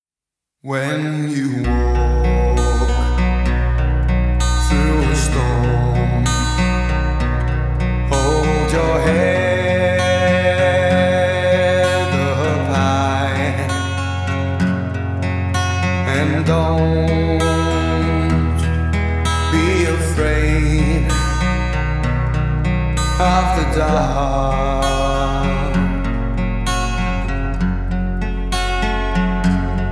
Genre: Alternative